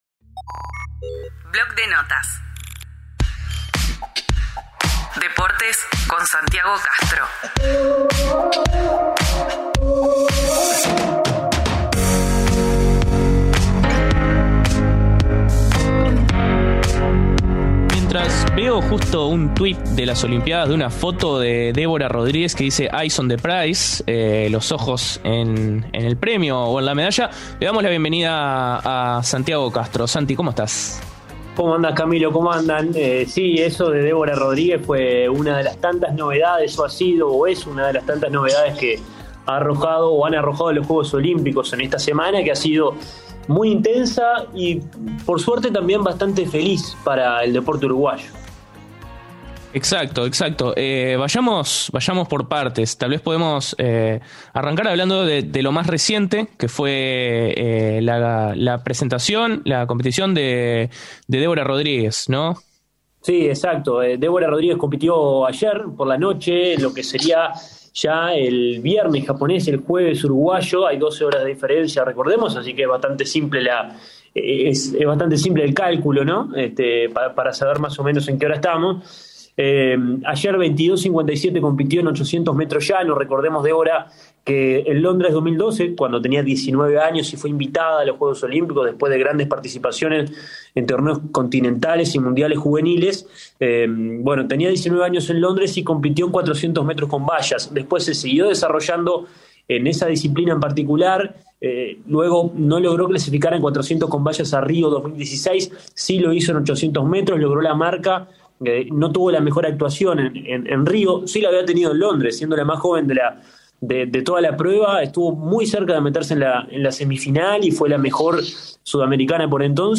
Comunidad Udelar, el periodístico de UNI Radio.